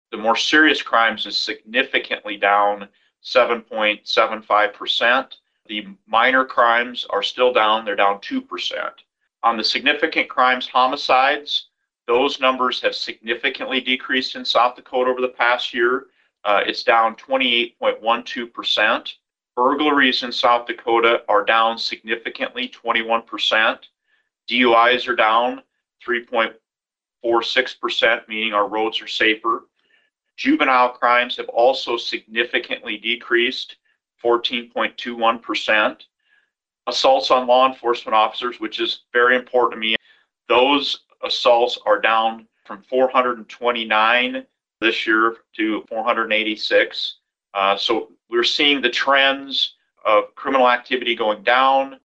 PIERRE, S.D.(HubCityRadio)- On Thursday, Attorney General Marty Jackley gave a report on crime rates in South Dakota for 2025.